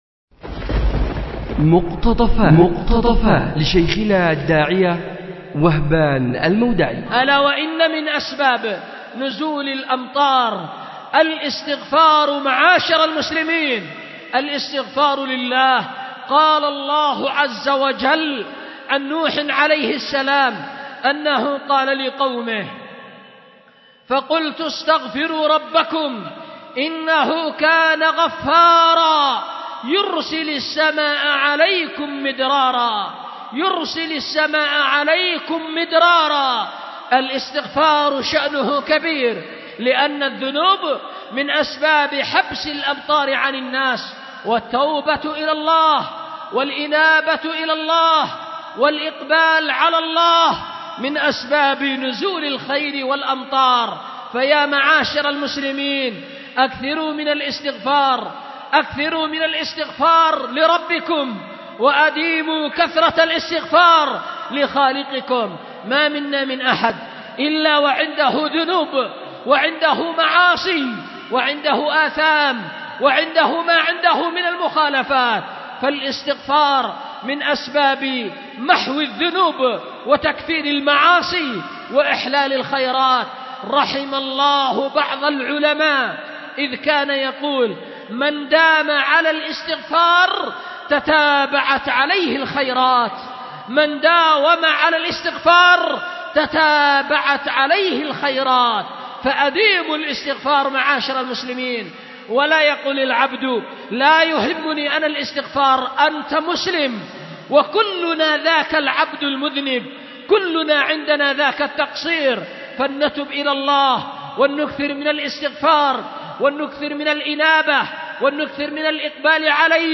أُلقي بدار الحديث للعلوم الشرعية بمسجد ذي النورين ـ اليمن ـ ذمار